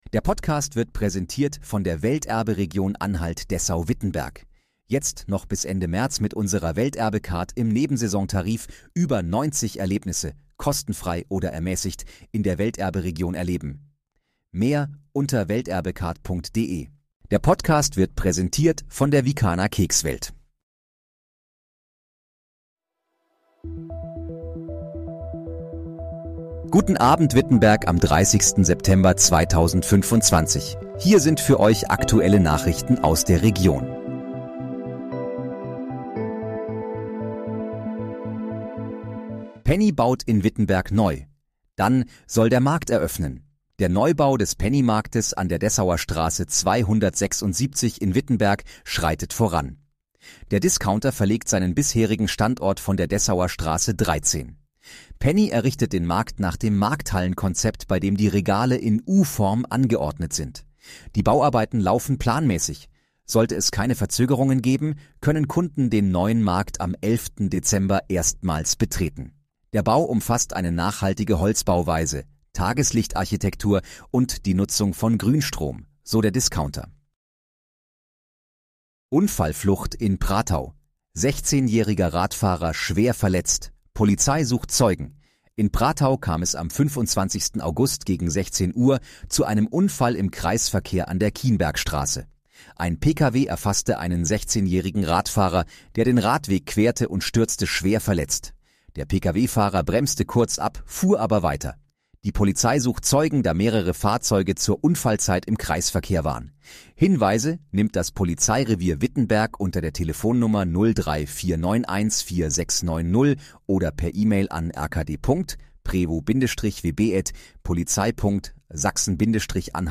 Guten Abend, Wittenberg: Aktuelle Nachrichten vom 30.09.2025, erstellt mit KI-Unterstützung
Nachrichten